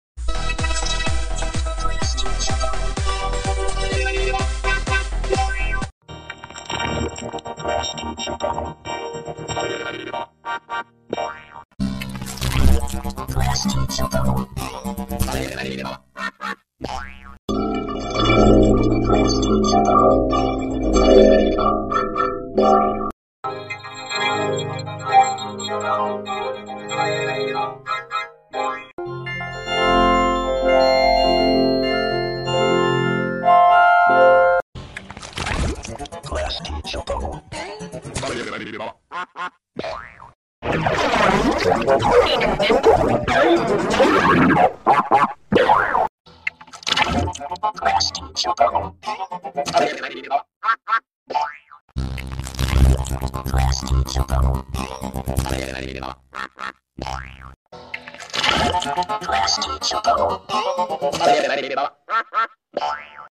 klasky csupo in Android vocoder sound effects free download